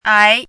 ái
拼音： ái
注音： ㄞˊ
ai2.mp3